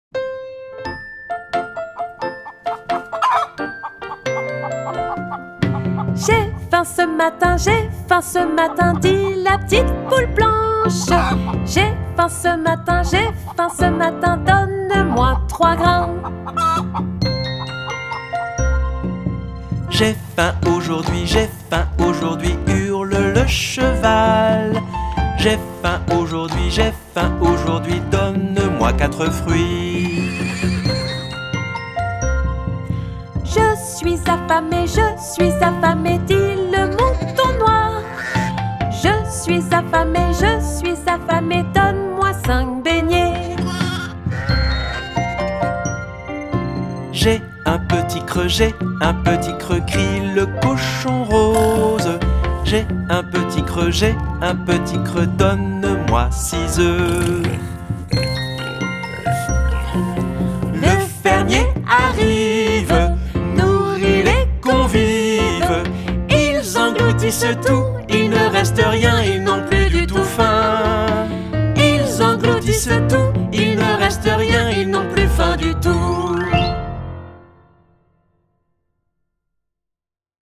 Comptines mathématiques
piano